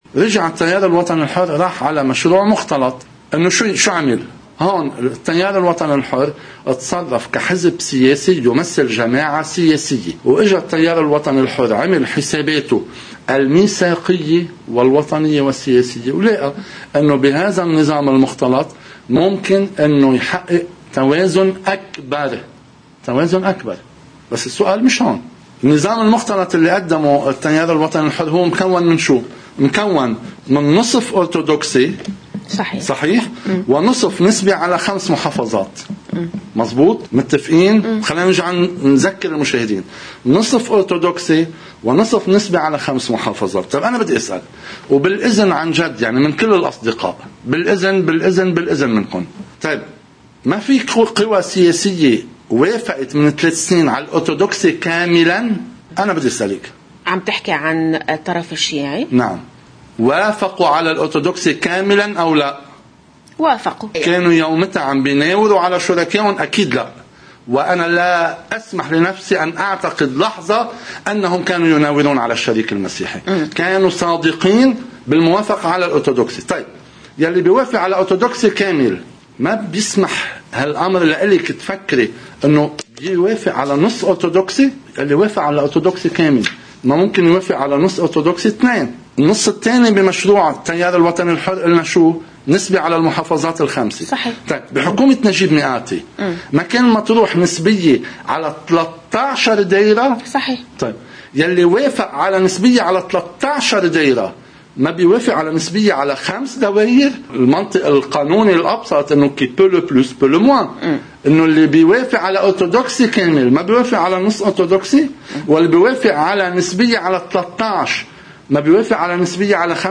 مقتطف من حديث مستشار رئاسة الجمهورية الإعلامي جان عزيز على قناة الـ”OTV”: